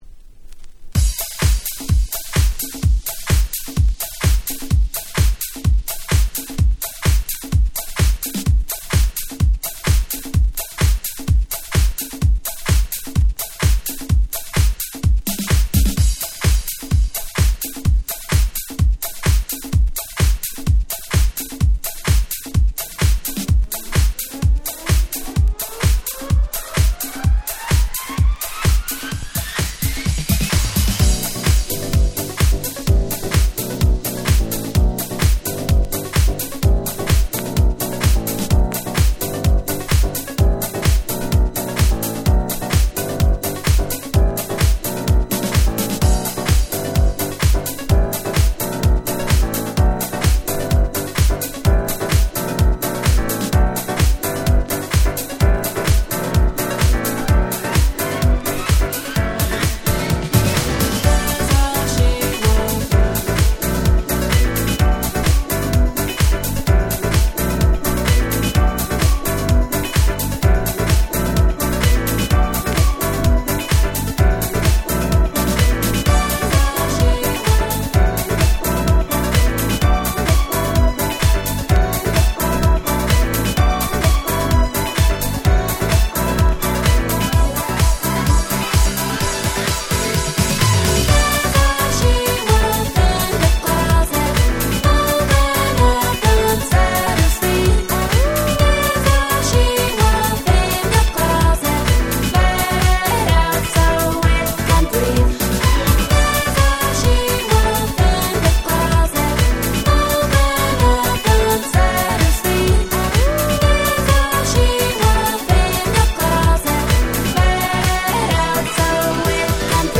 09' Super Hit R&B / Pops !!